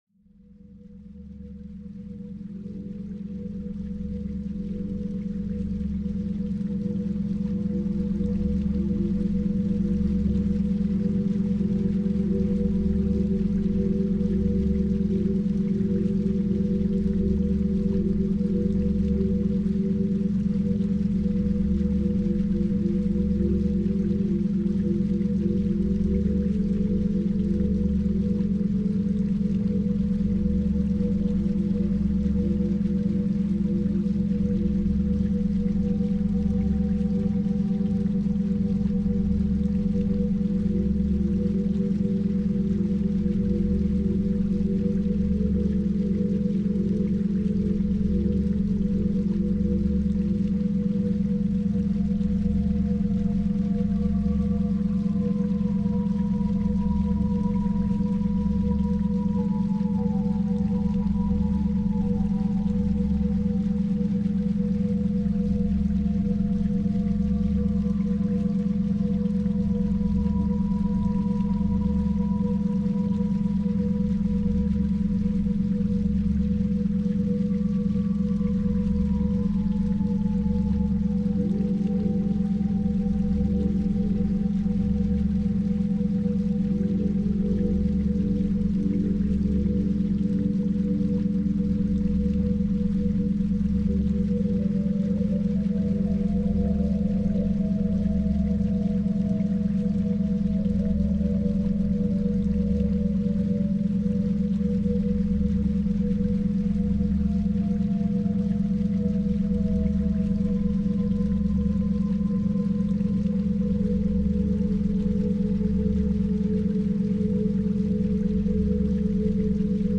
Searching for the perfect background sound to elevate your concentration and motivation?
Background Sounds